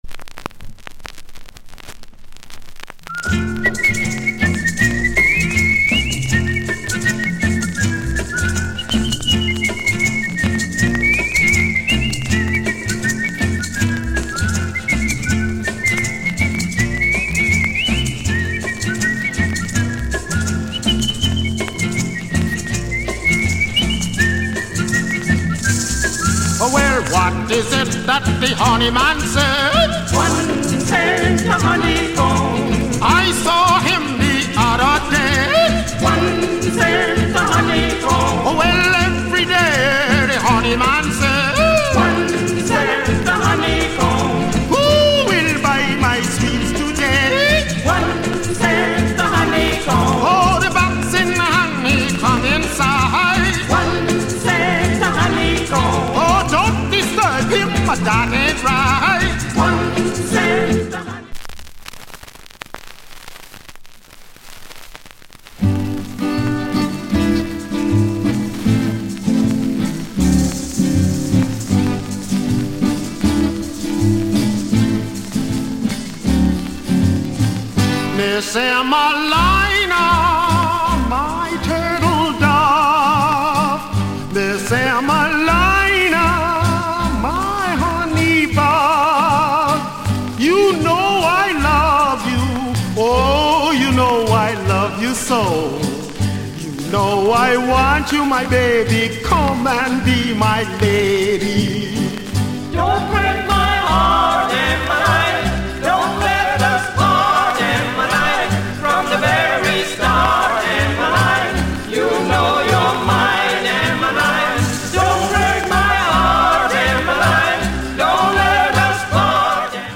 * 57 US World / Carib Roots